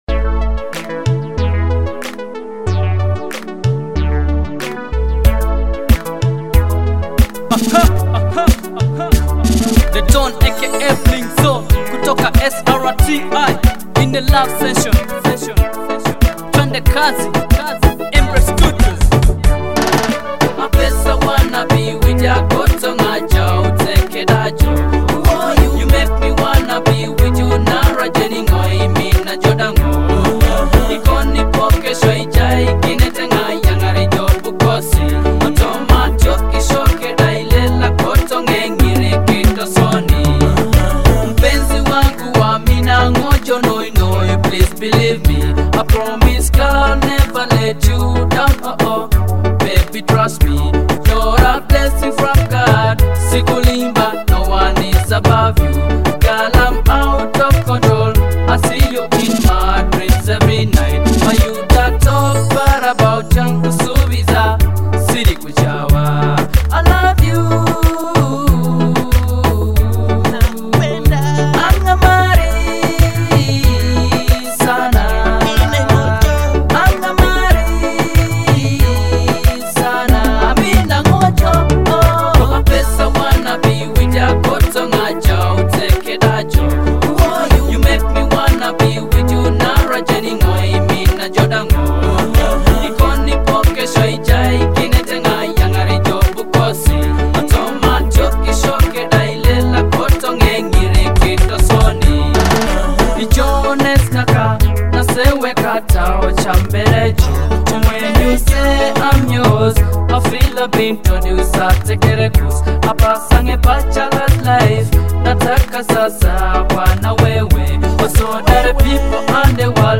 a catchy Teso love song celebrating a special girl.